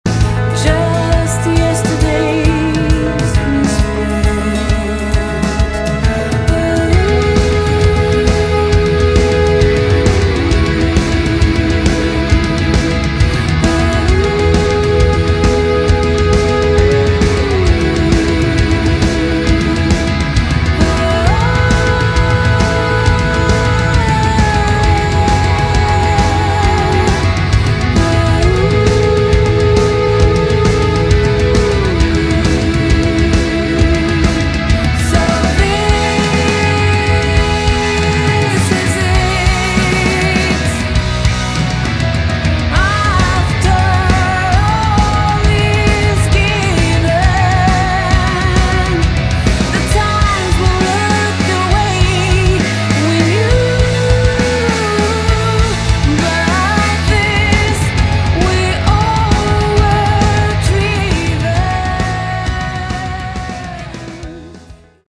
Category: Modern Hard Rock/Metal
vocals
guitars, bass, keyboards